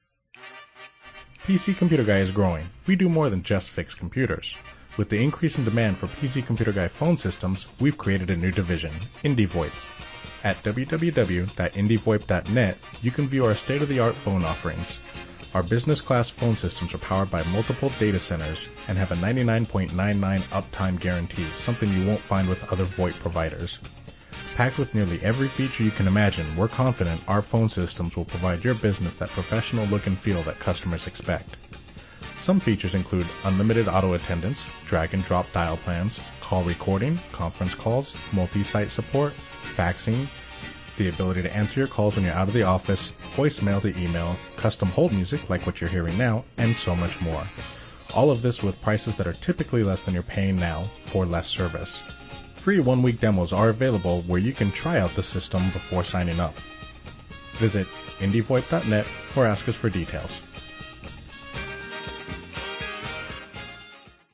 Custom hold sound clips. Instead of hearing nothing or generic music on hold, we’ve recorded “Tech Tips” and information about our company. So if we have to put the person on hold, we can make use of that hold time by providing information about our company and products.
Hold-IndyVoip.mp3